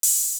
SG - Open Hat 2.wav